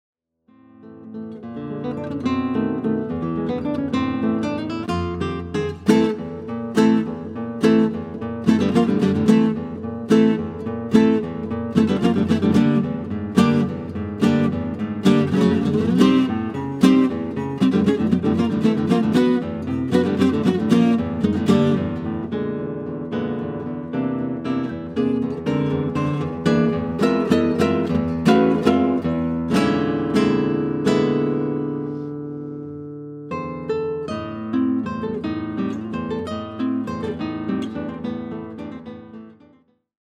A clear sonority is evident throughout his recording.